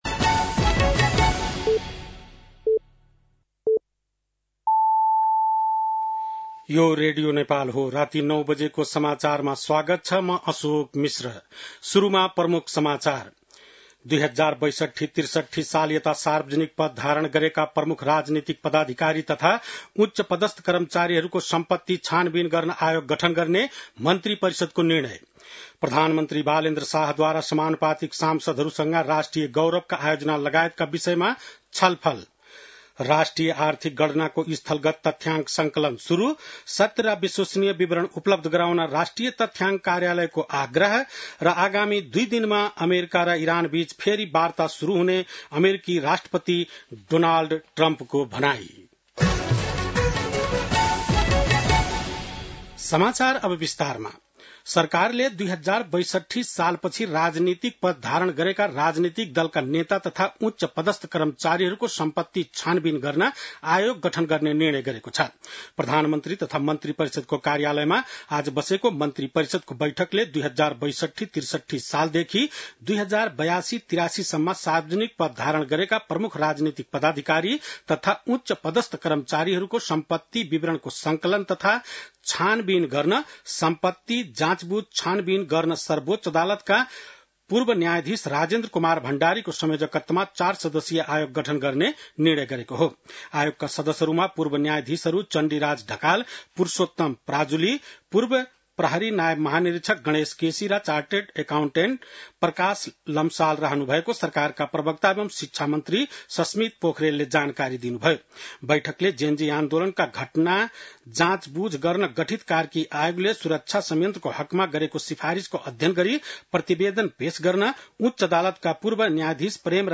बेलुकी ९ बजेको नेपाली समाचार : २ वैशाख , २०८३
9-pm-nepali-news-1-02.mp3